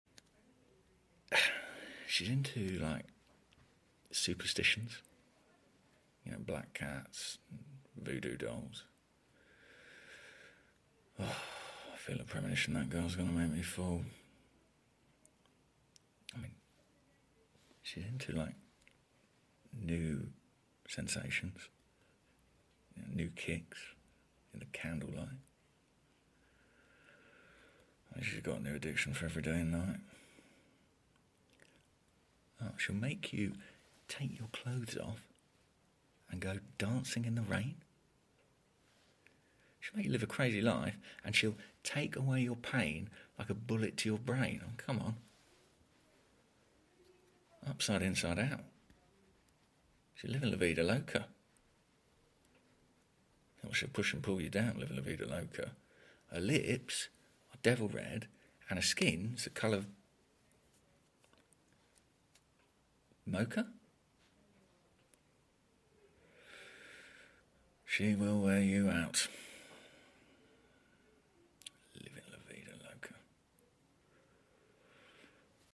🎭 Dramatic Monologue: Livin’ La sound effects free download